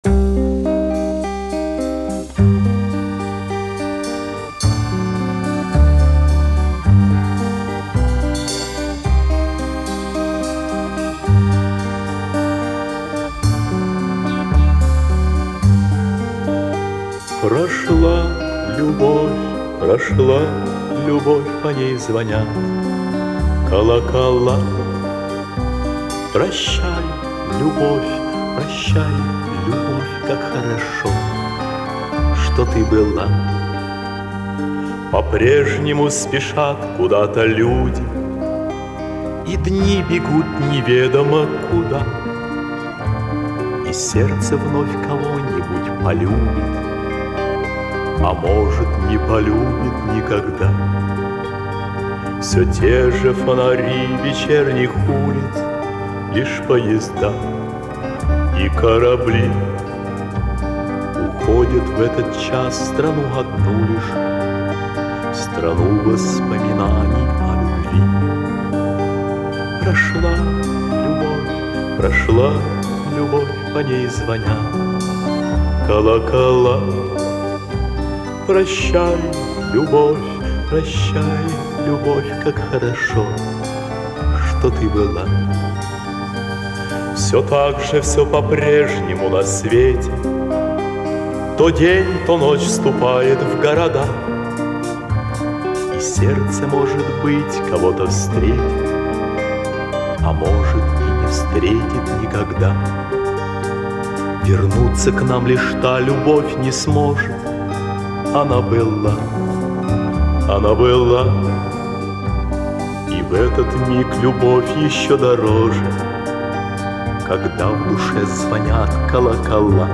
И ещё одна просьба, если это не затруднит, опознать исполнителя этой мелодии,пожалуйста.
Очень похоже на японцев начала , середины 70-х. Ихний стиль.